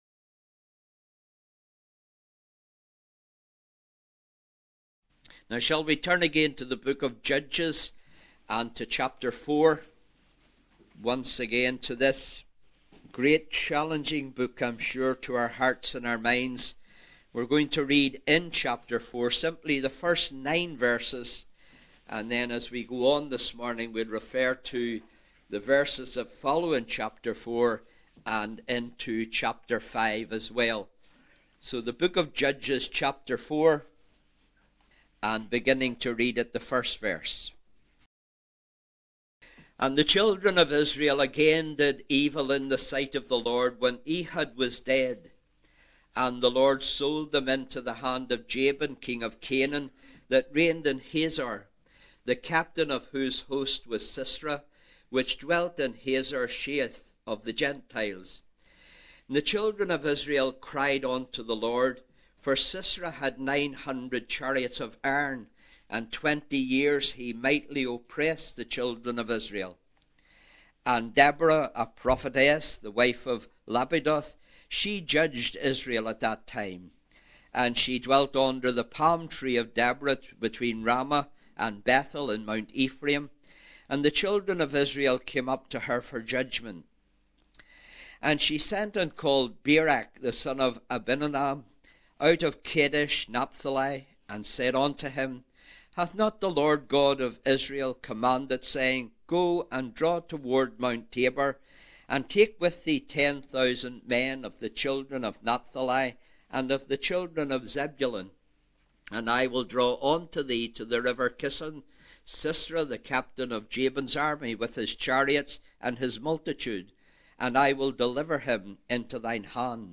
The Book of Judges. 10 sermons